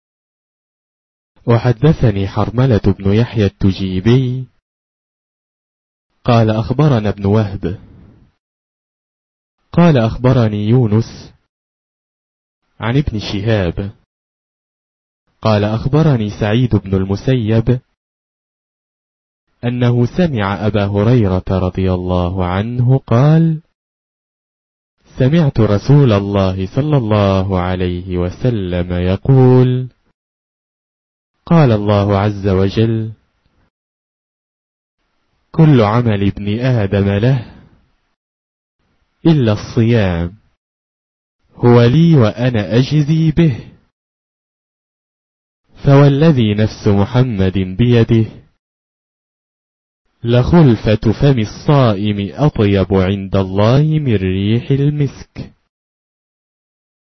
الـكتب الناطقة باللغة العربية